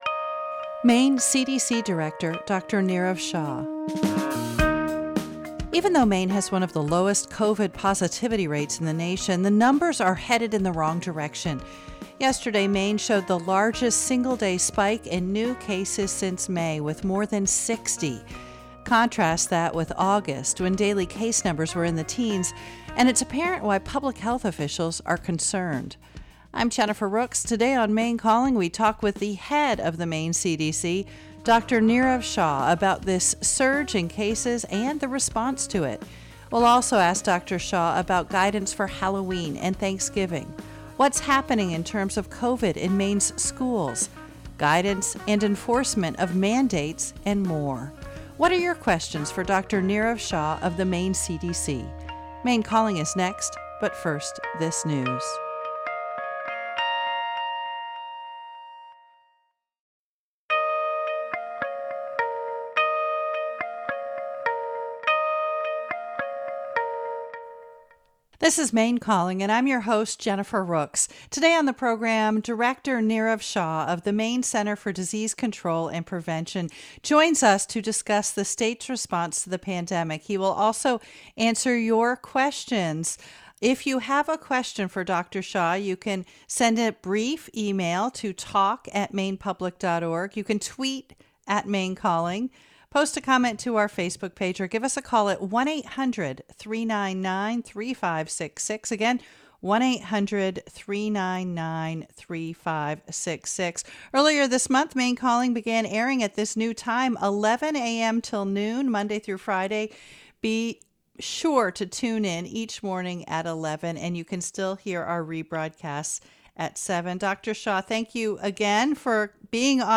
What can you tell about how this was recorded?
Maine Calling is a live, call-in radio program offering enlightening and engaging conversations on a wide range of topics.